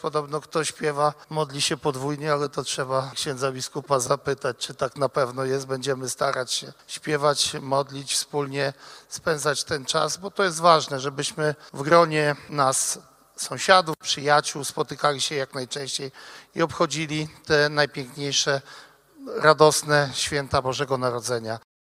Minister rolnictwa i rozwoju wsi Stefan Krajewski przypomniał, że „Podlaskie Kolędowanie” jest już lokalną łomżyńską tradycją.